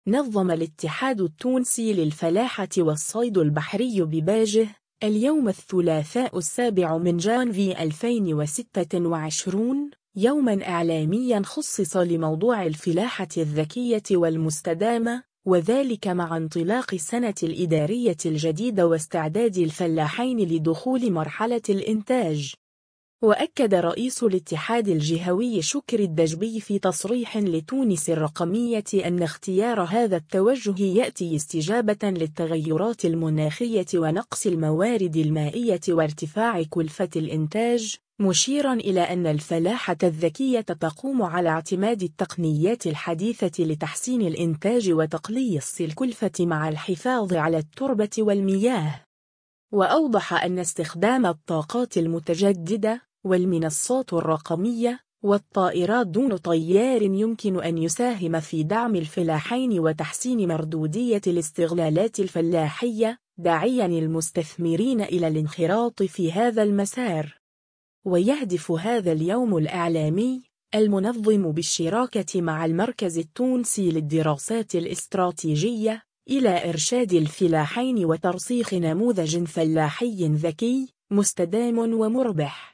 نظم الاتحاد التونسي للفلاحة والصيد البحري بباجة، اليوم الثلاثاء 7 جانفي 2026، يومًا إعلاميًا خُصّص لموضوع الفلاحة الذكية والمستدامة، وذلك مع انطلاق السنة الإدارية الجديدة واستعداد الفلاحين لدخول مرحلة الإنتاج.